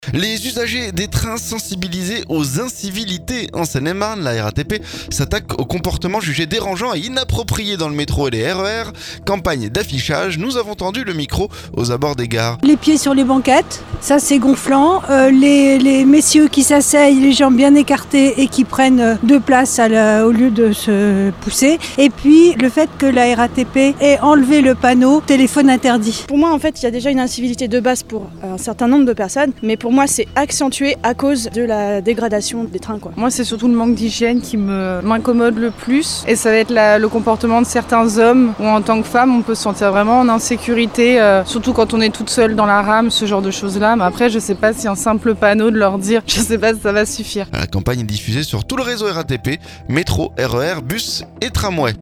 Nous avons tendus le micro aux abords des gares.